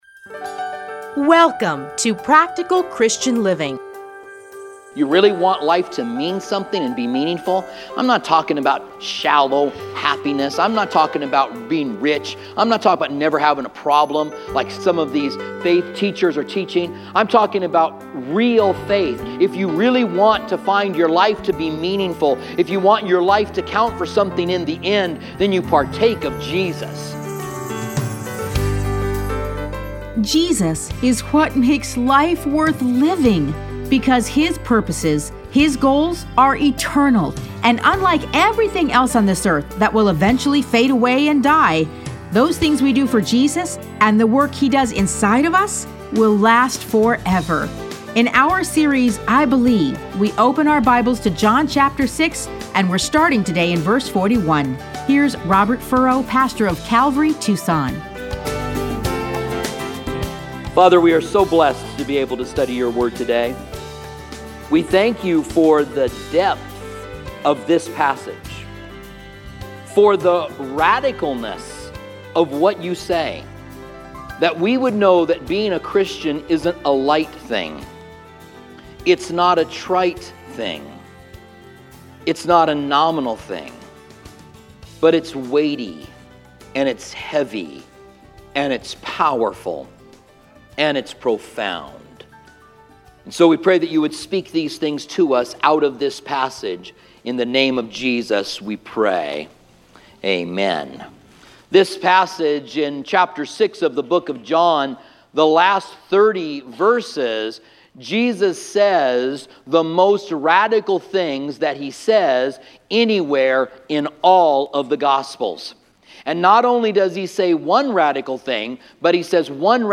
Listen to a teaching from John 6:41-71.